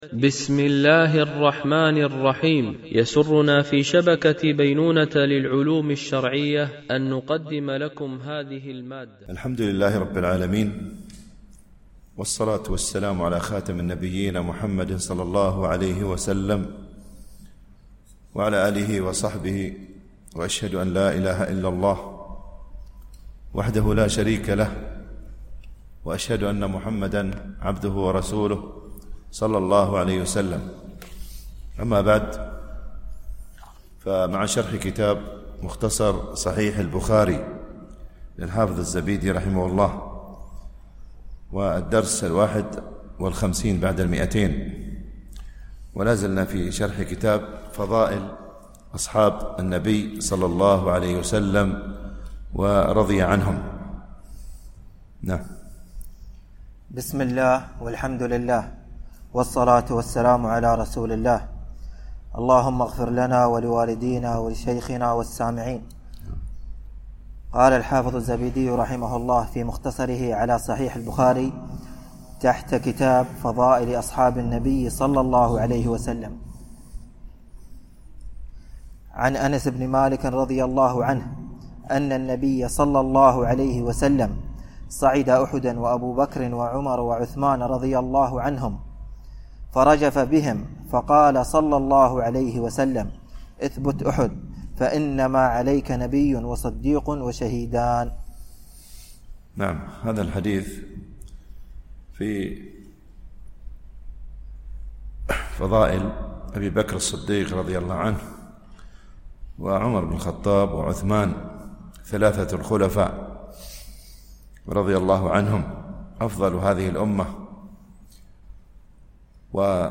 - الجزء الثالث - الحديث 1527 - 1531 ) الألبوم: شبكة بينونة للعلوم الشرعية التتبع: 251 المدة: 53:05 دقائق (24.32 م.بايت) التنسيق: MP3 Mono 44kHz 64Kbps (CBR)